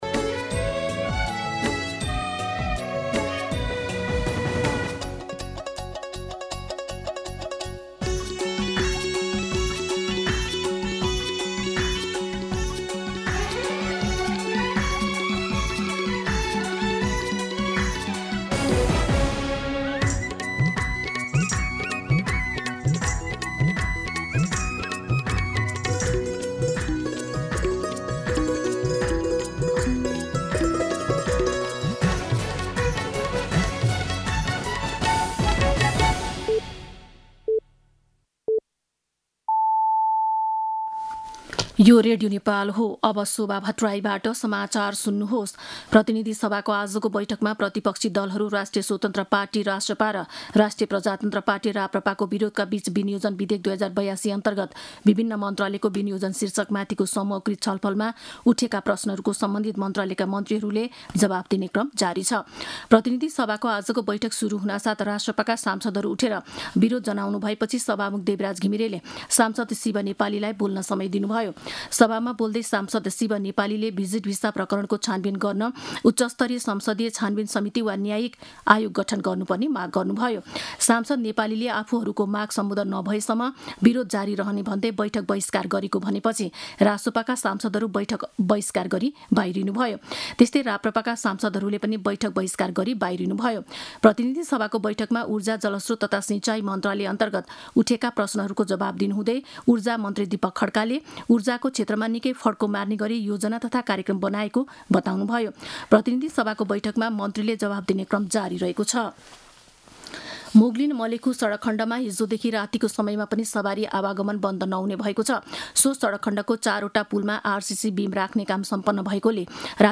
An online outlet of Nepal's national radio broadcaster
मध्यान्ह १२ बजेको नेपाली समाचार : ९ असार , २०८२